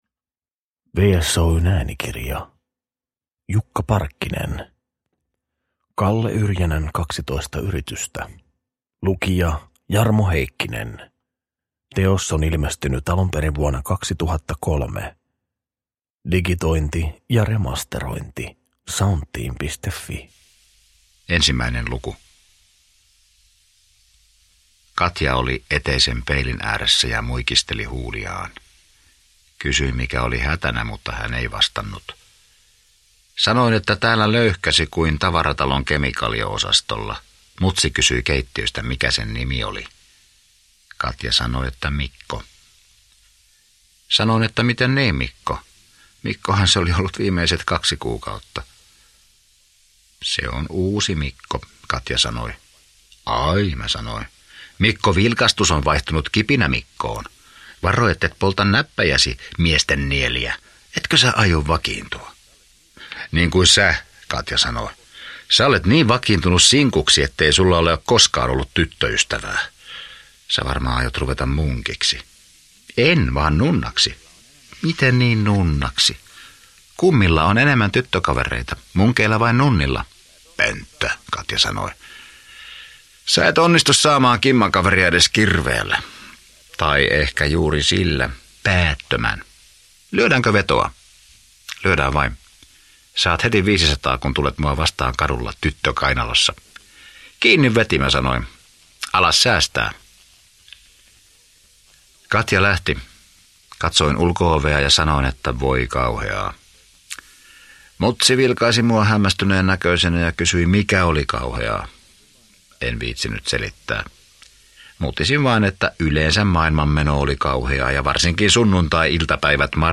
Kalle-Yrjänän kaksitoista yritystä – Ljudbok – Laddas ner